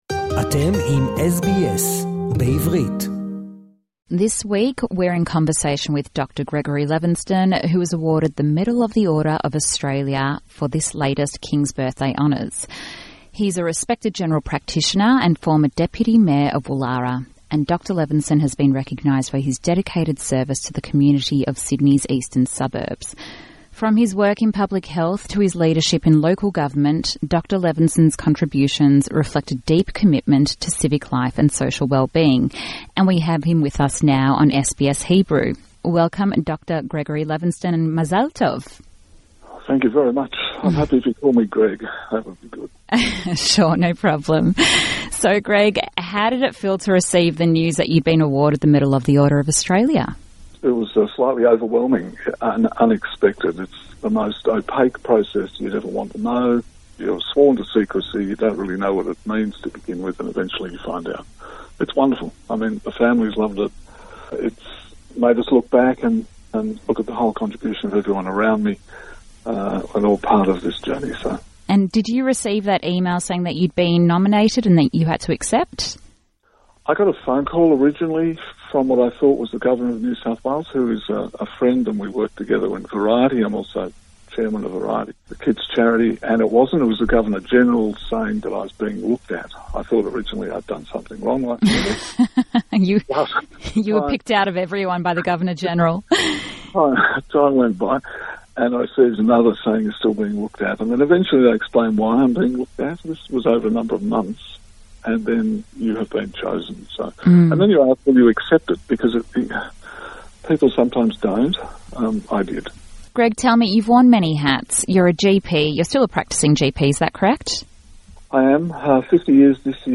In this interview, we meet Dr Gregory Levenston a respected doctor recently awarded an OAM for his tireless service to the community. Dr Levenston has worked in general practice in Sydney’s eastern suburbs and worked as deputy mayor of Woollahra Municipal Council.